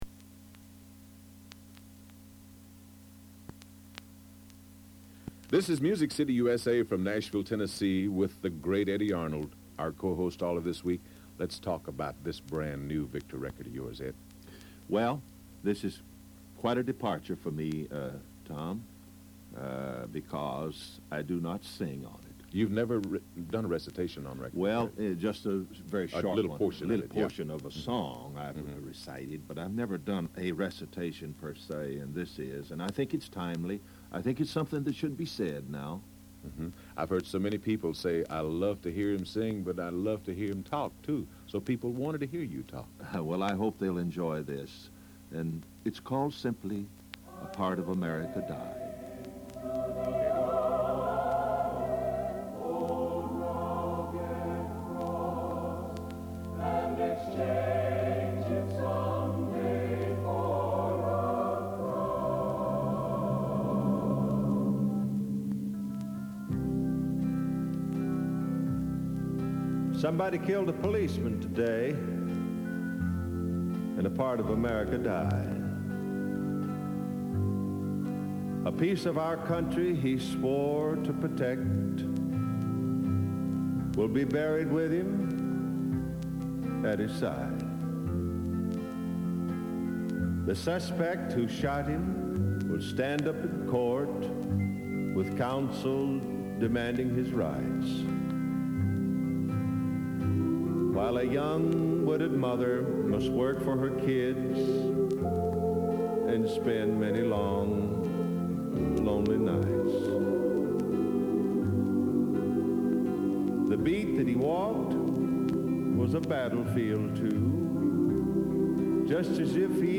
Co-Host Eddy Arnold
Eddy recites A Part of America Died/Faron does another commercial for "BC Powder"/